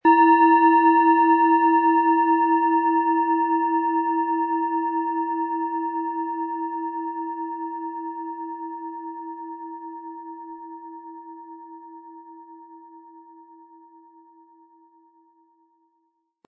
Hopi Herzton
Nach Jahrhunderte alter Tradition von Hand getriebene Planetenklangschale Hopi-Herzton.
Der kräftige Klang und die außergewöhnliche Klangschwingung der traditionellen Herstellung würden uns jedoch fehlen.
Im Preis enthalten ist ein passender Klöppel, der die Töne der Schale schön zum Schwingen bringt.
HerstellungIn Handarbeit getrieben
MaterialBronze